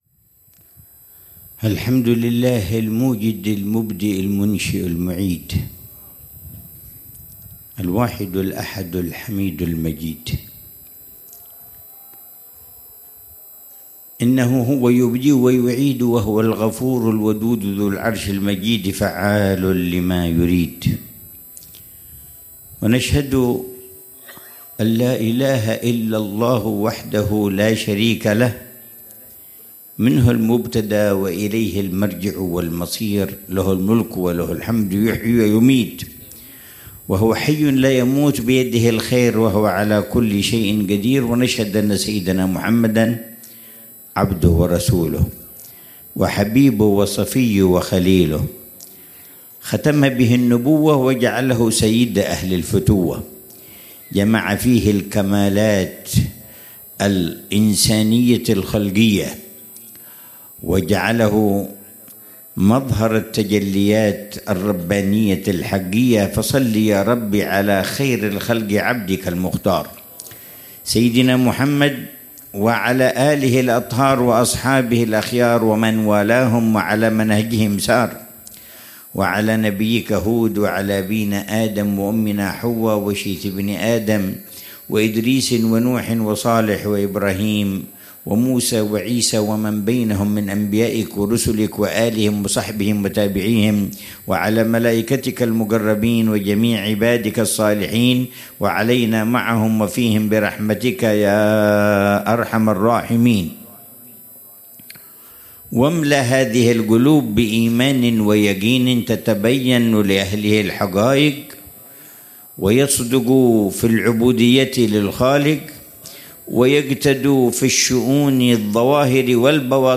محاضرة العلامة الحبيب عمر بن محمد بن حفيظ في المجلس الرابع من مجالس الدعوة إلى الله في شعب النبي هود عليه السلام، ضمن محاور (تقوية الإيمان وتقويم السلوك) ، ليلة السبت 9 شعبان 1446هـ بعنوان: